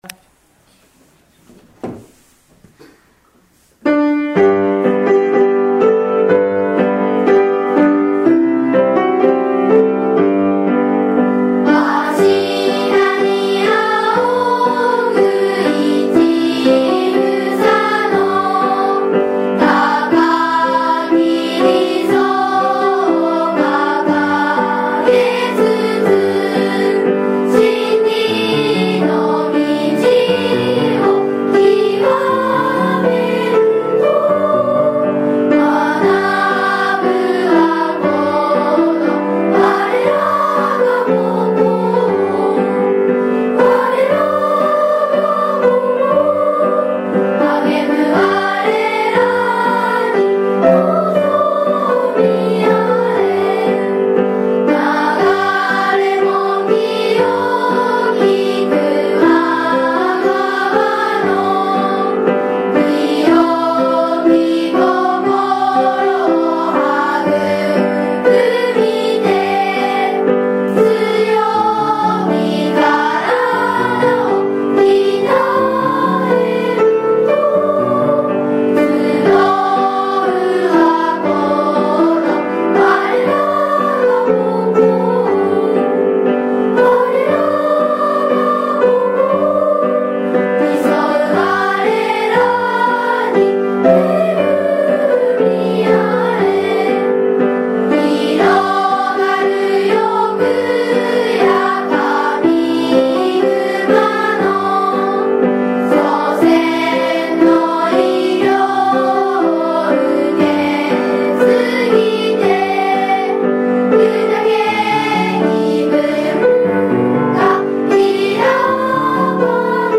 【１　校歌】
湯前小学校校歌（ピアノ伴奏あり）.mp3